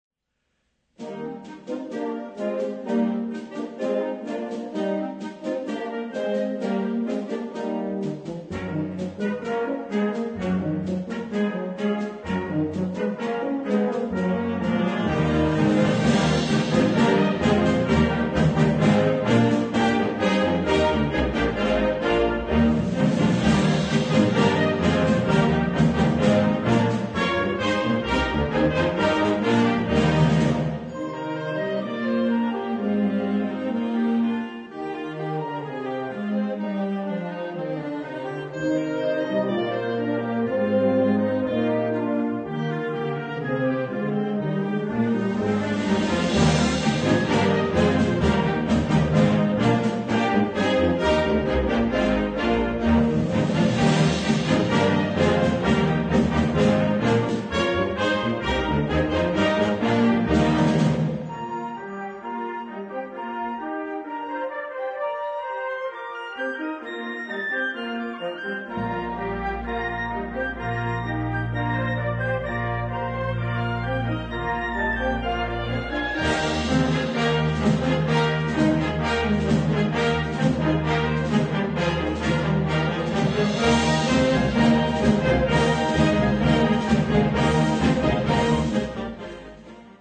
Bezetting Ha (harmonieorkest)